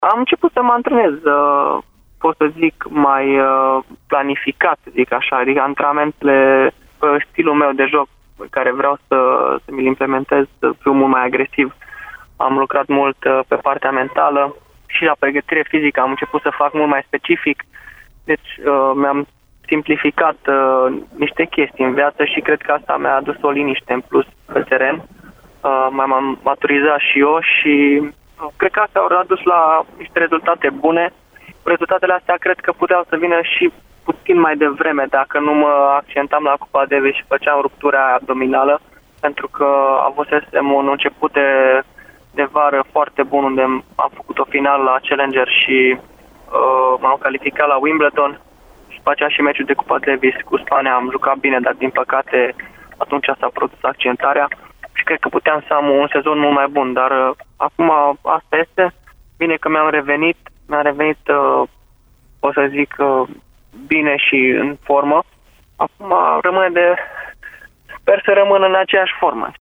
Marius a vorbit la Radio Timișoara, week-end-ul trecut despre forma sportivă tot mai bună și despre schimbările din 2016: